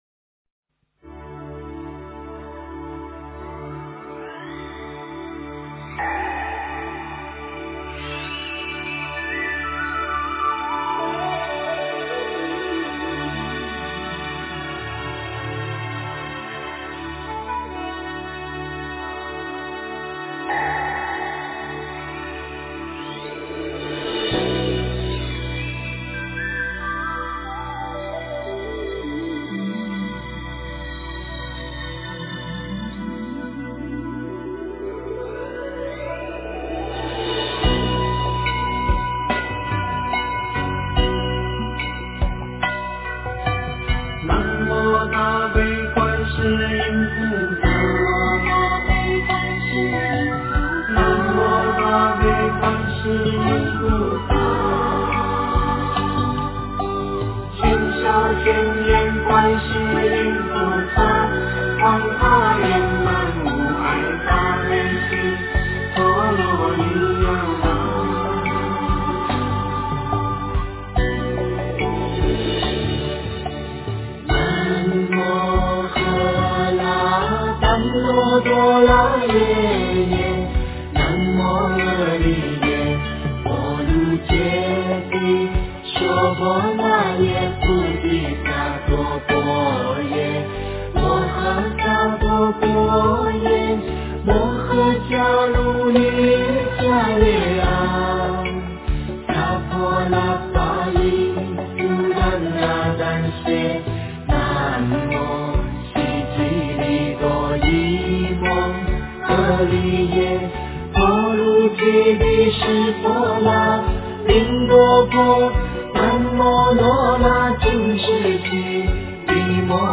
大悲咒 诵经 大悲咒--佚名 点我： 标签: 佛音 诵经 佛教音乐 返回列表 上一篇： 心经 下一篇： 般若波罗蜜多心经 相关文章 楞严咒-第四会--黄慧音 楞严咒-第四会--黄慧音...